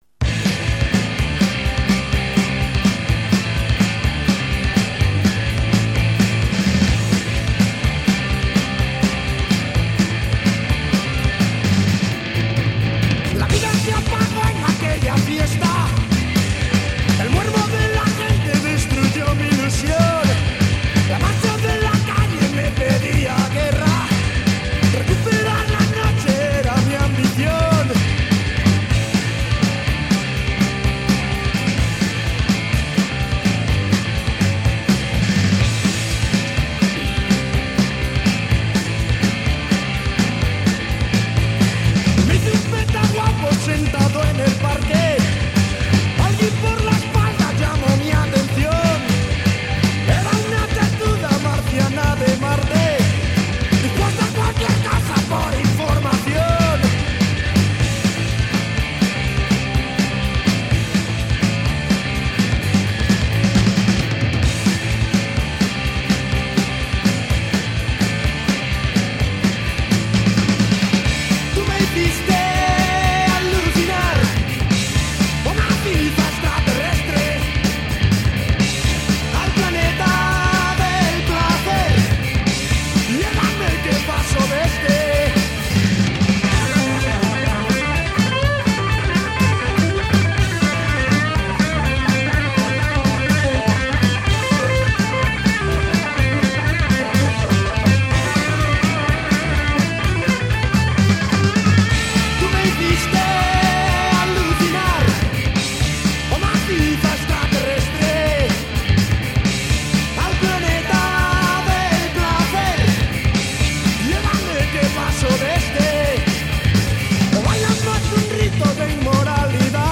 Esta versión es infinitamente más dura